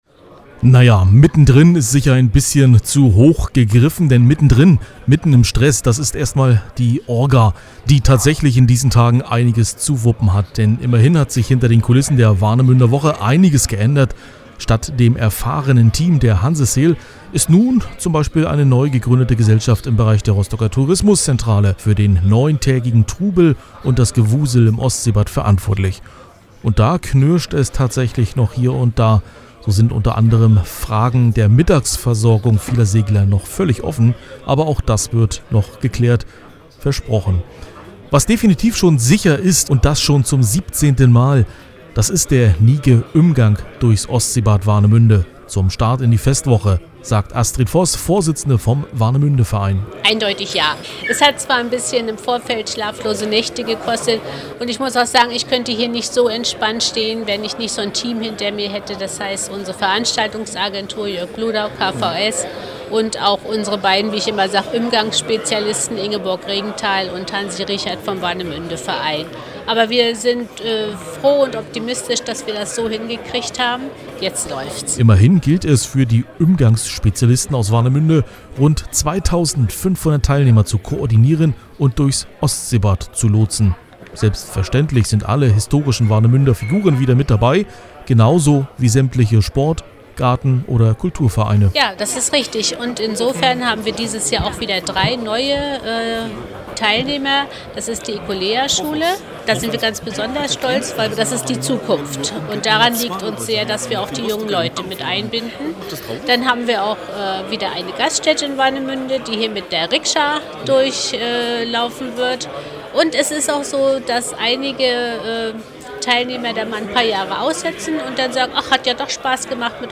So viele Menschen kommen traditionell zur Warnemünder Woche. Die Warnemünder Woche: ein Mix aus Sport und Kultur, und unser Redakteur war mittendrin: